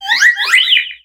Cri de Flabébé dans Pokémon X et Y.